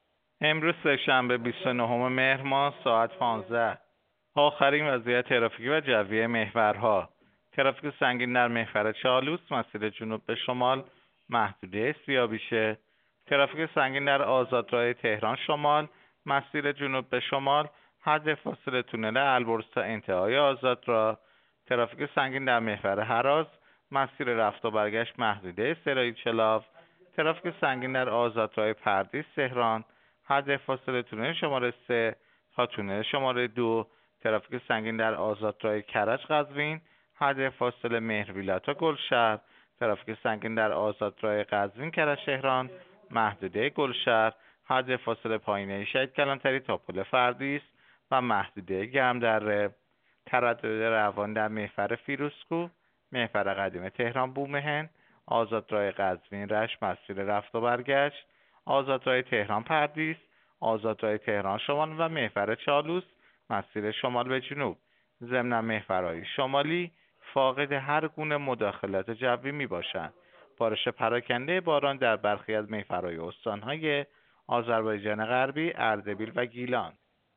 گزارش رادیو اینترنتی از آخرین وضعیت ترافیکی جاده‌ها ساعت ۱۵ بیست‌ونهم مهر؛